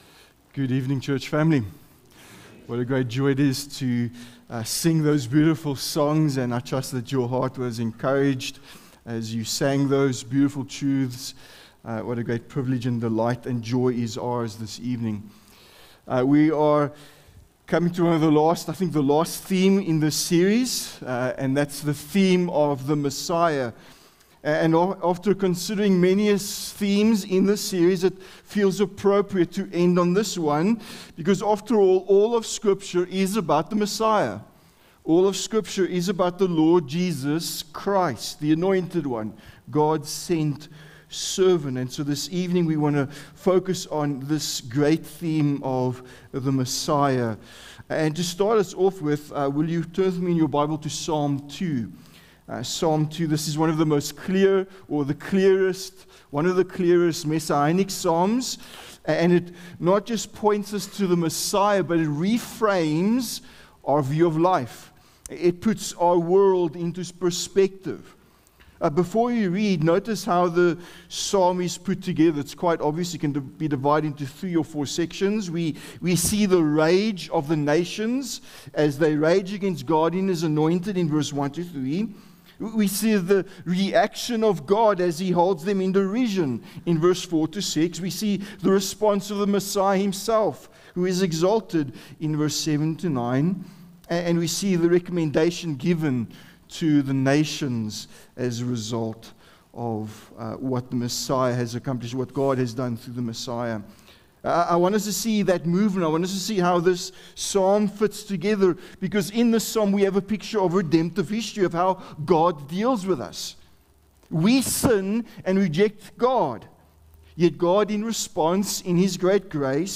Sermons | Honeyridge Baptist Church